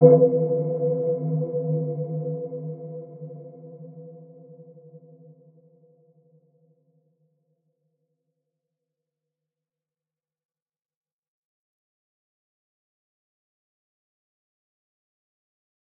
Dark-Soft-Impact-B4-mf.wav